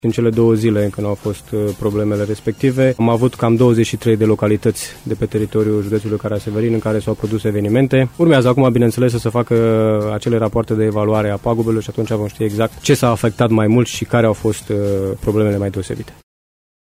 Şeful ISU Semenic, colonel Titus Susan a menţionat că judeţul Caraş-Severin a scăpat relativ uşor din aceste inundaţii, comparativ cu ce pagube s-au înregistrat în alte zone din ţară: